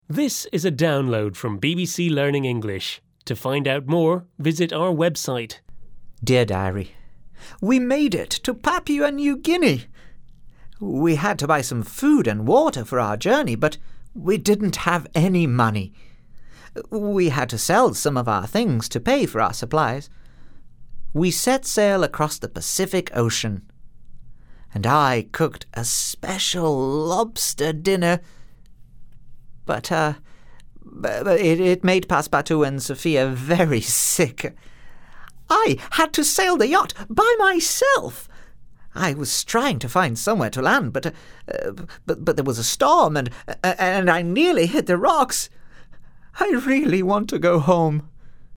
unit-8-4-1-u8_eltdrama_therace_audio_diary_download.mp3